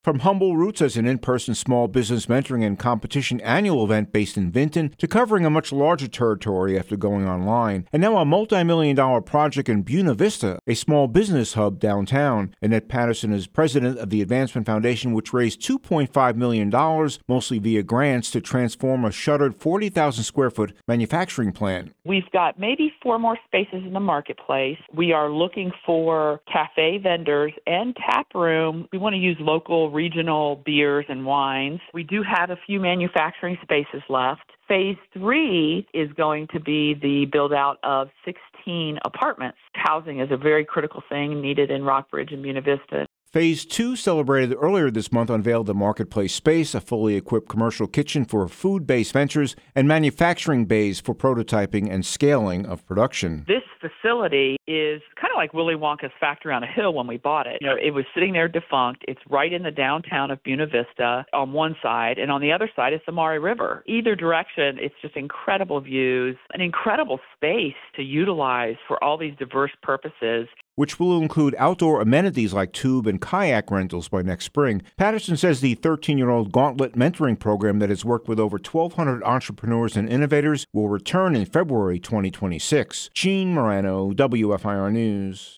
More than three years in the making, the most ambitious project yet from The Advancement Foundation – creator of The Gauntlet business mentorship program – has just celebrated Phase 2 of what it calls the Virginia Innovation Accelerator in Buena Vista. An update